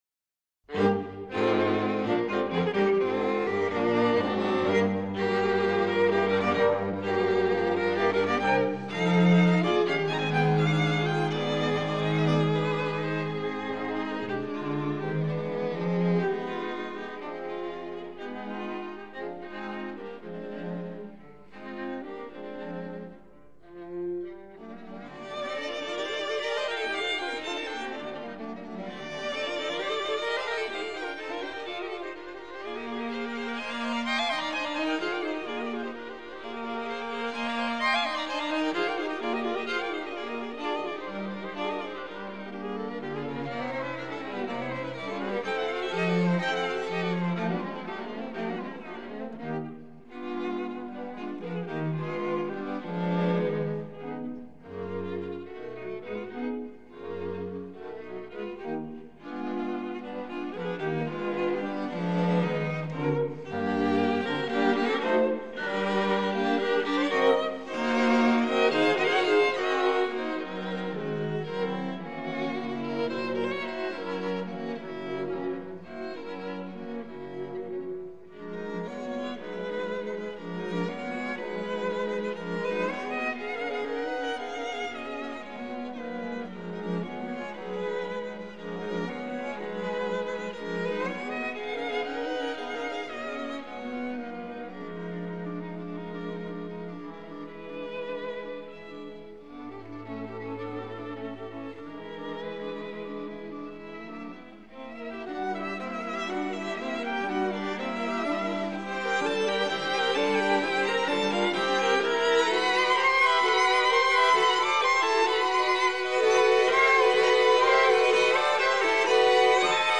德彪西被誉为印象派音乐大师。
第一乐章：生动而非常明确地